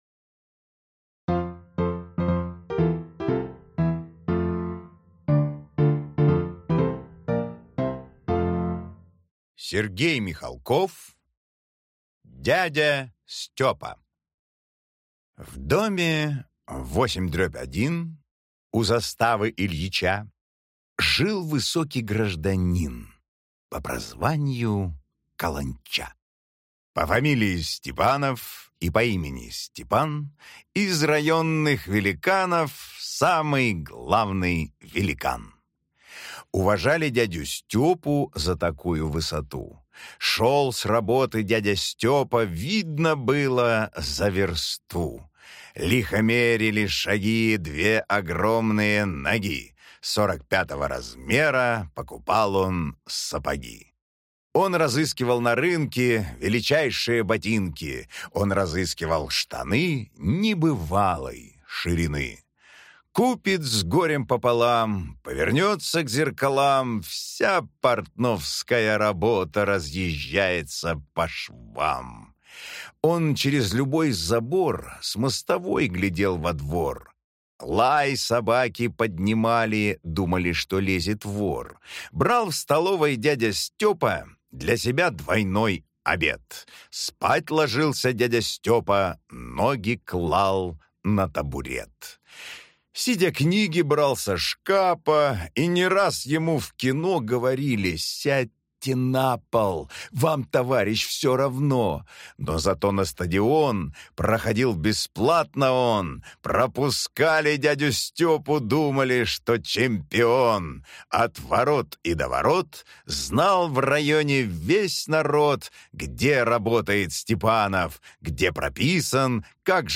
Аудиокнига Дядя Стёпа | Библиотека аудиокниг